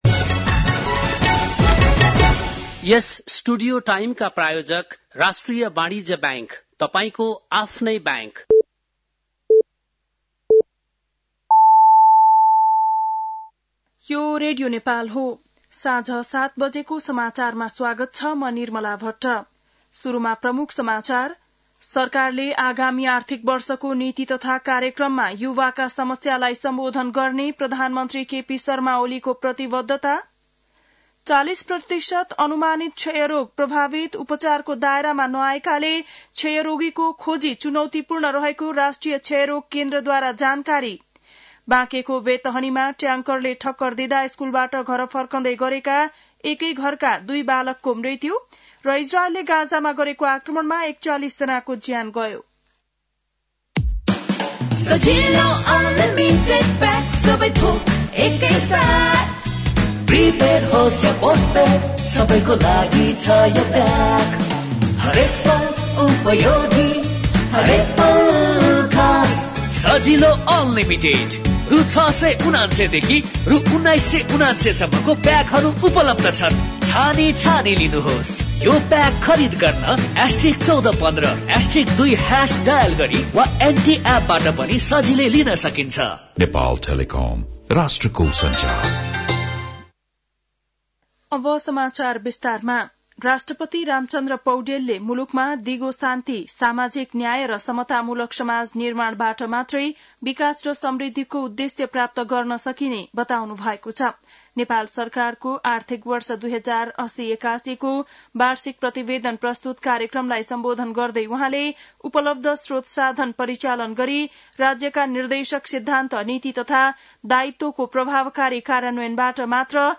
बेलुकी ७ बजेको नेपाली समाचार : १० चैत , २०८१
7-pm-news-5.mp3